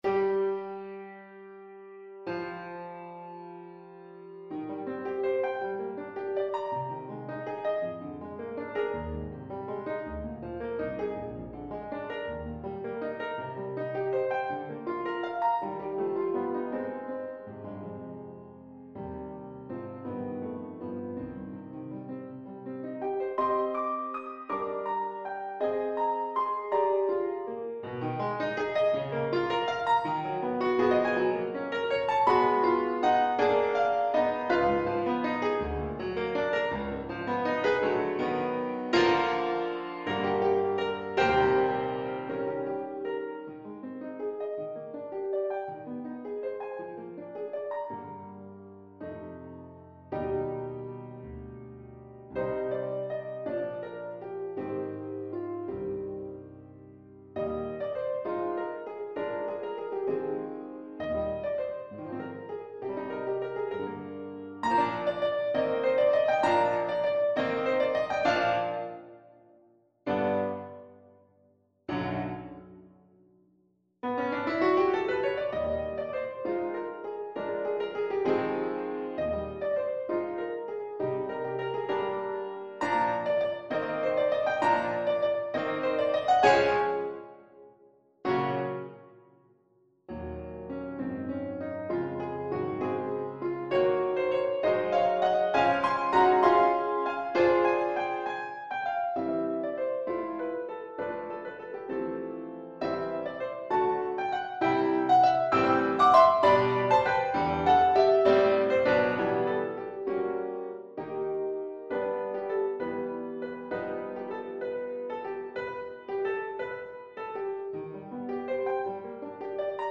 6/8 (View more 6/8 Music)
Classical (View more Classical Trumpet Music)